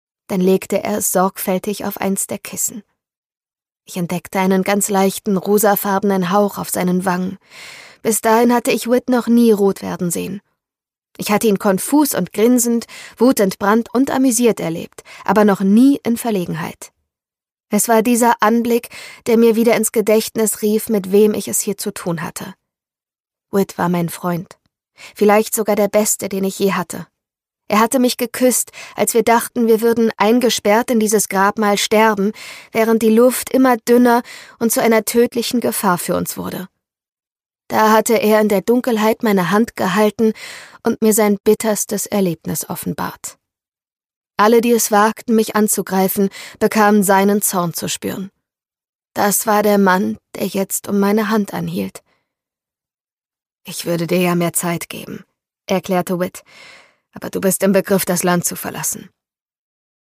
Isabel Ibañez: Where the Library hides - Geheimnisse des Nil, Band 2 (Ungekürzte Lesung)
Produkttyp: Hörbuch-Download